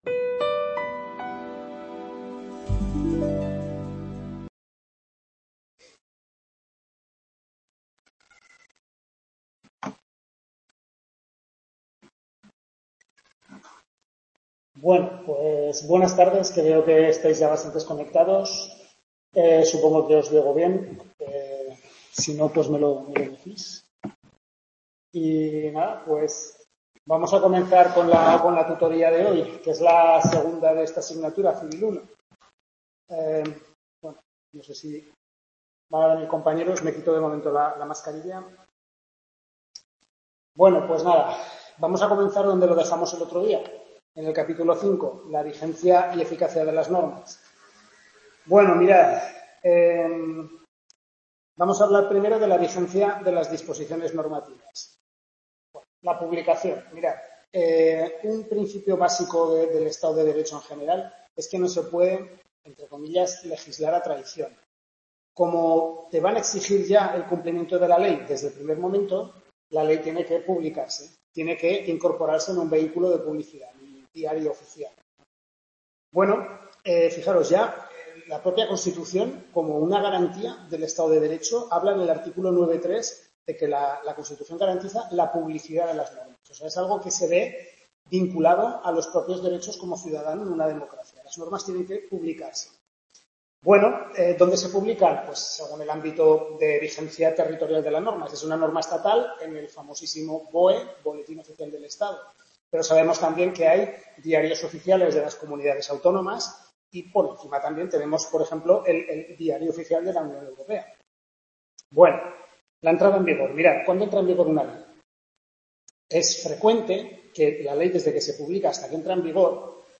Segunda tutoría del primer cuatrimestre, Civil I, centro de Calatayud, capítulos 5-8 del Manual del Profesor Lasarte